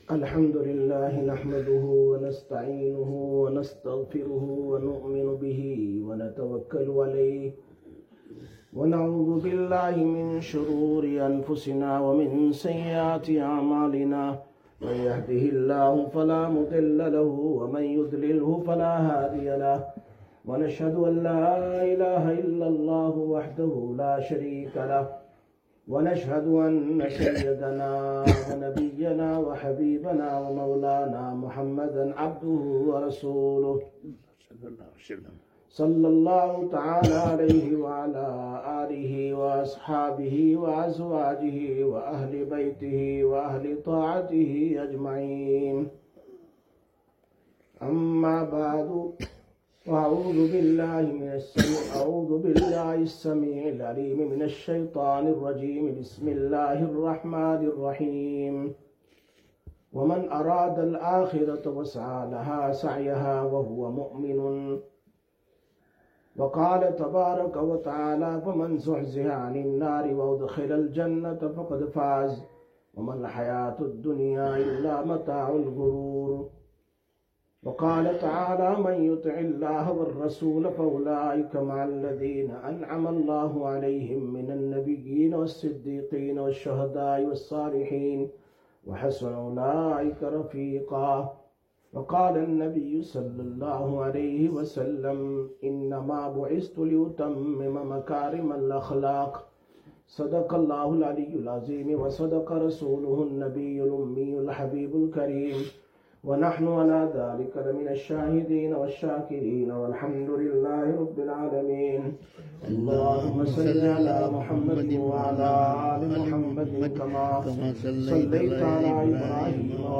30/01/2026 Jumma Bayan, Masjid Quba